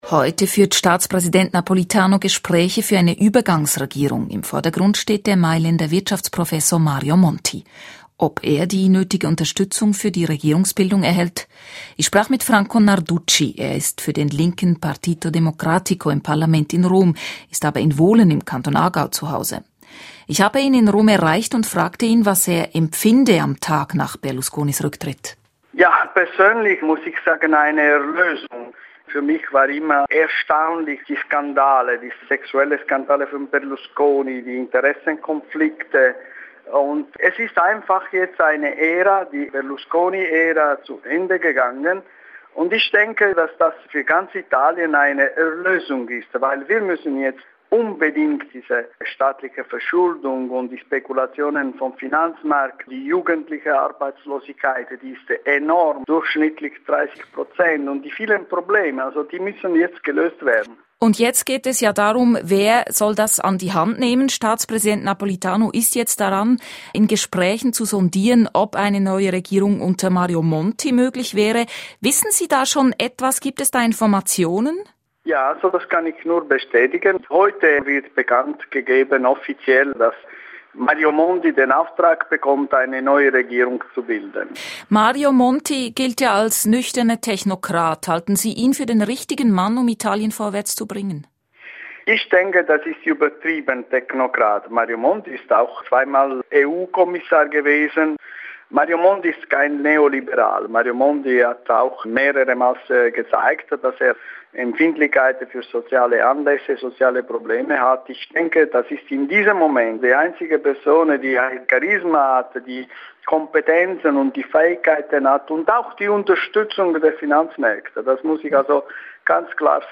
In Italien ist mit dem Rücktritt von Premier Berlusconi eine Ära zu Ende gegangen. Dazu ein Gespräch mit Franco Narducci, er ist Abgeordneter im italienischen Parlament und lebt in Wohlen AG.